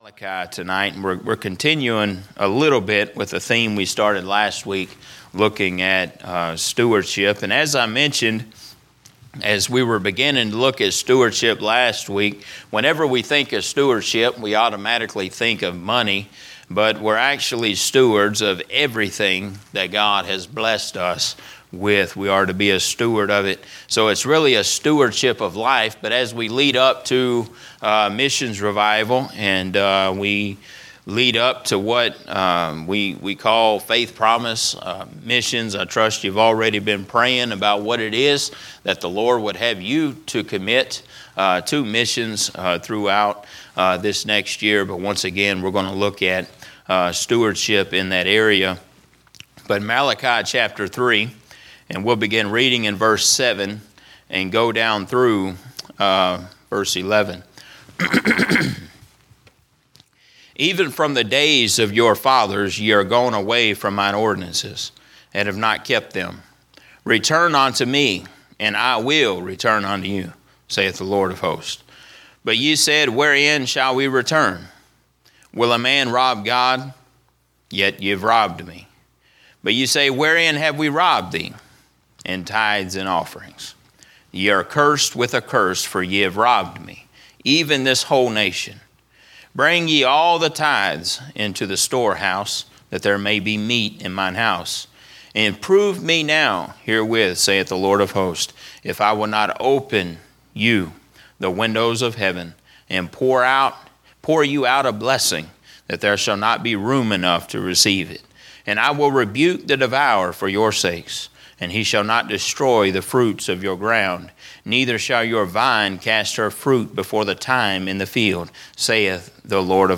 " Missions Revival " Sermons preached during our annual missions revival